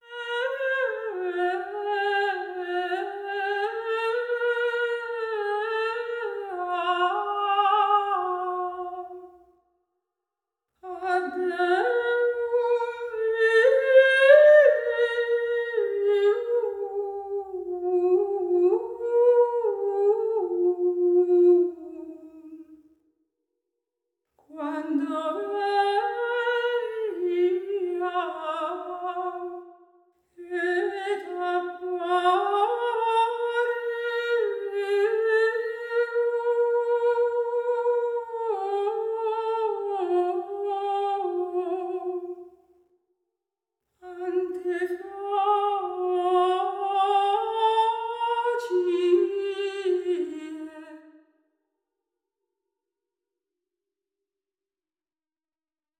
PSALM41__timeDomain_Position2.wav